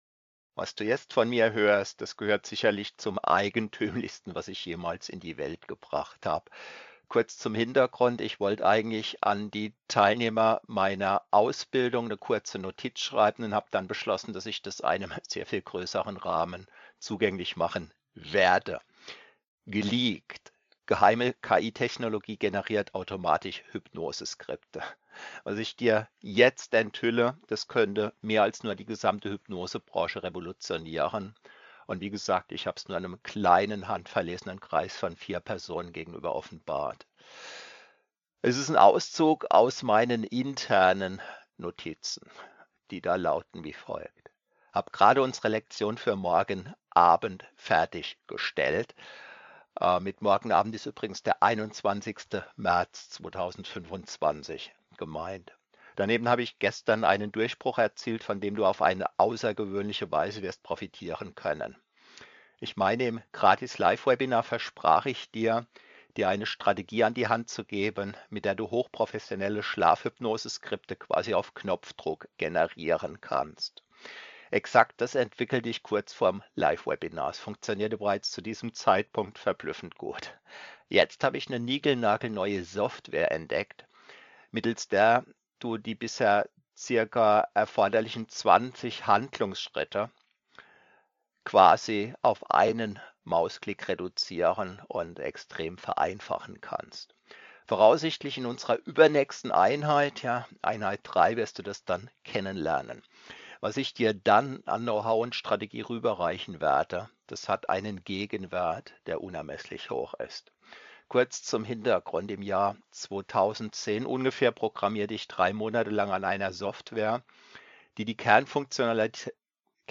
Webinar